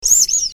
resources/phase_4/audio/sfx/clock02.ogg at 98d20729ac7745c941b692abfcc47f95743db8b3
clock02.ogg